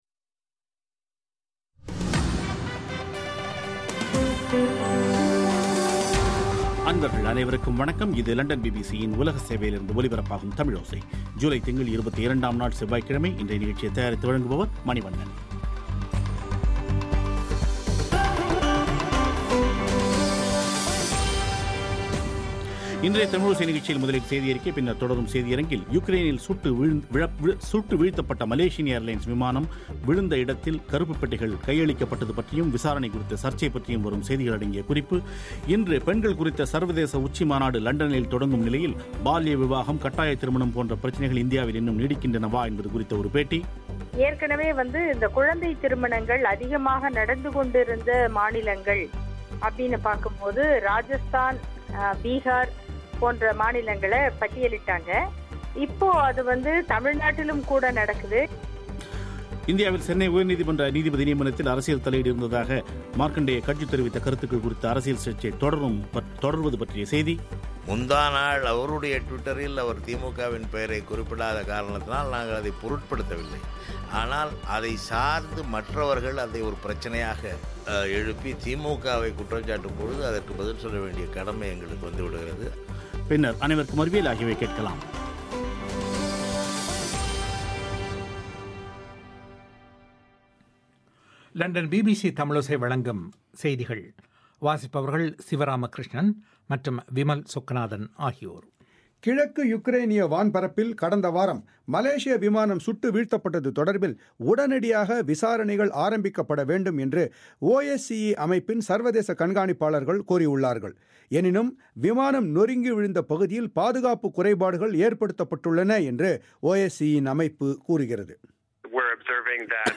இன்றைய தமிழோசை நிகழ்ச்சியில் முதலில் செய்தி அறிக்கை, பின்னர் தொடரும் செய்தி அரங்கில்